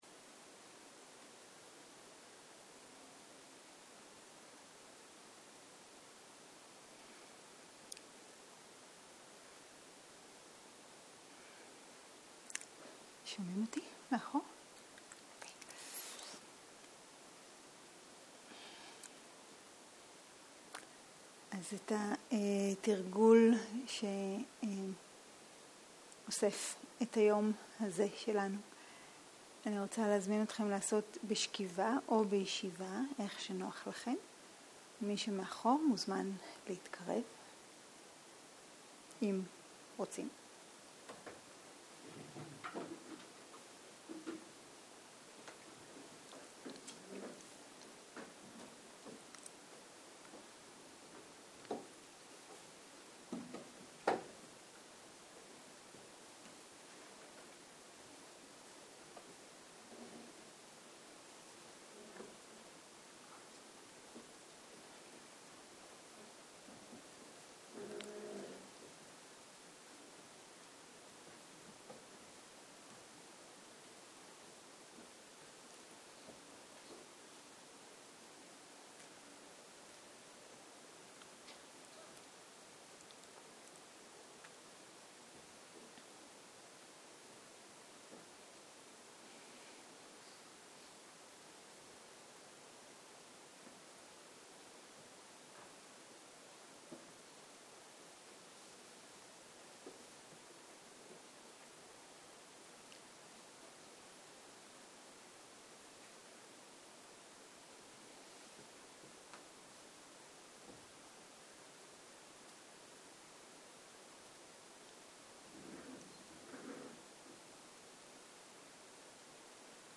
Dharma type: Guided meditation שפת ההקלטה